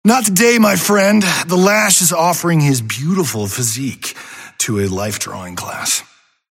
Lash voice line - Not today my friend, The Lash is offering his beautiful physique to a life-drawing class.